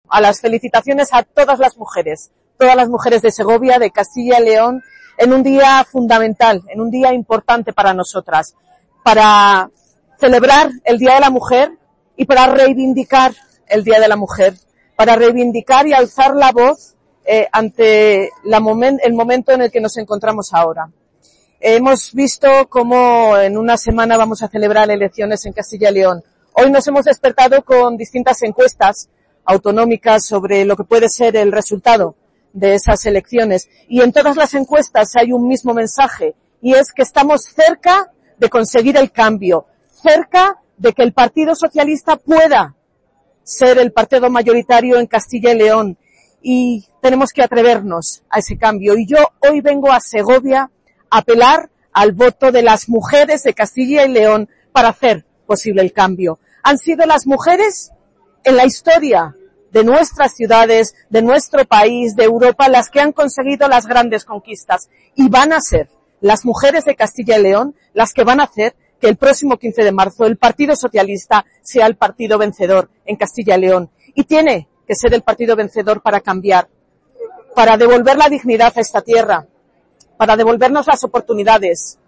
Durante su intervención, Iratxe García ha felicitado a todas las mujeres en un día “fundamental para celebrar, pero también para reivindicar”.